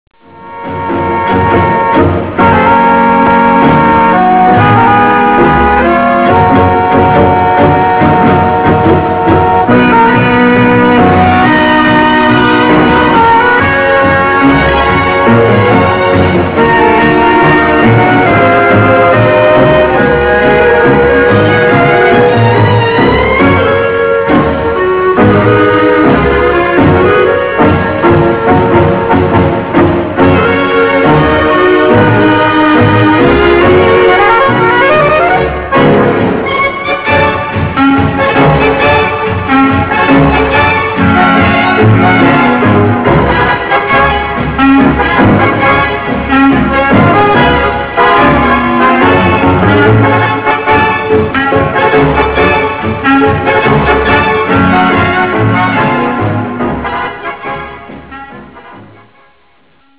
Tango argentino
Original Track Music